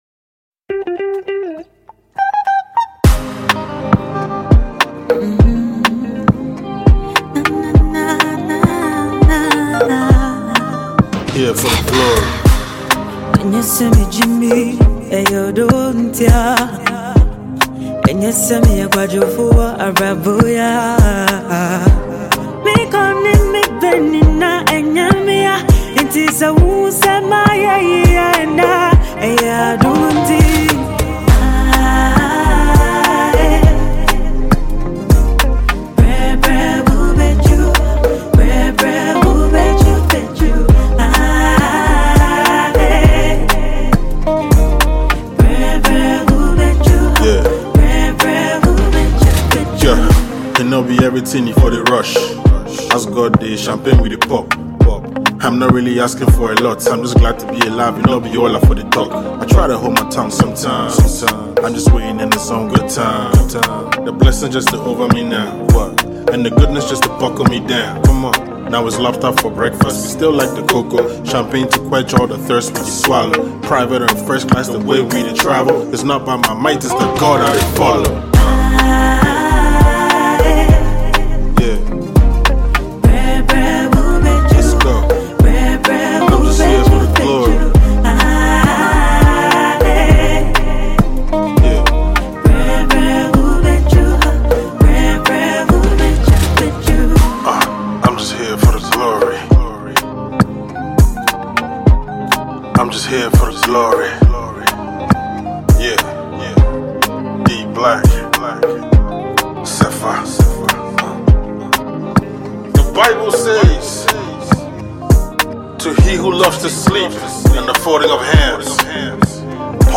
Ghanaian hiphop recording musician
songstress